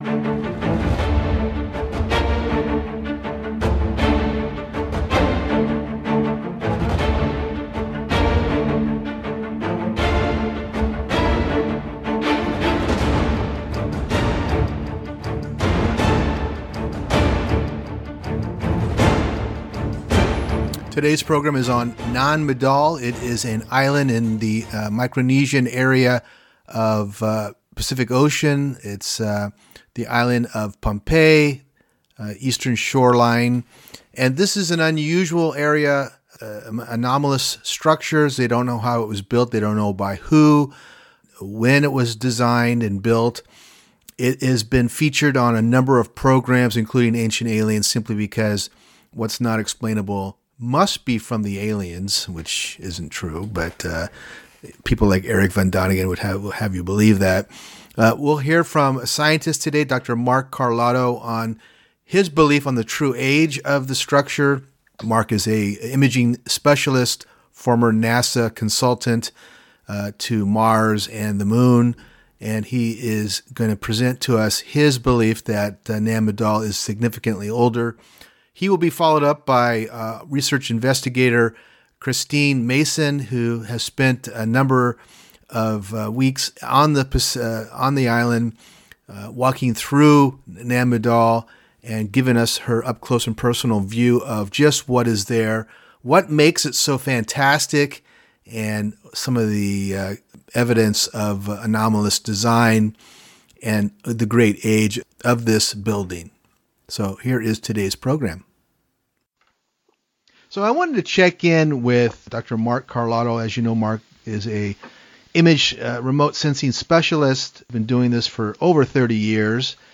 Allatura-TV Interview (12/8/20)